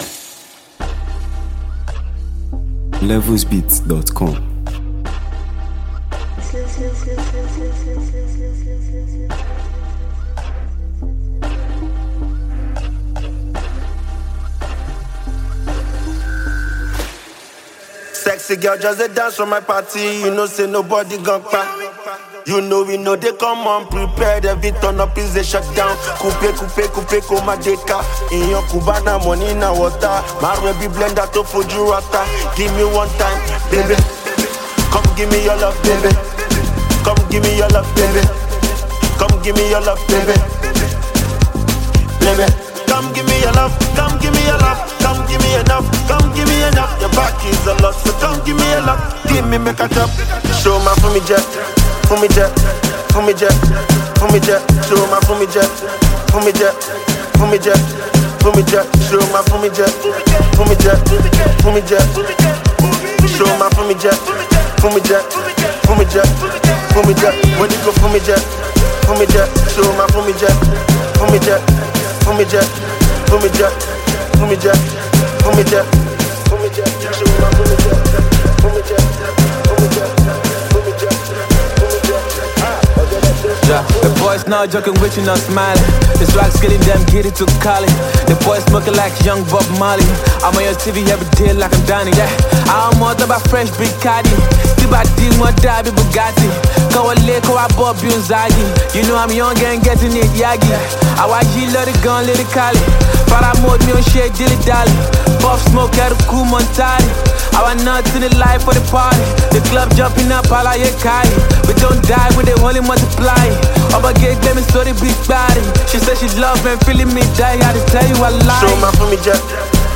With its infectious rhythm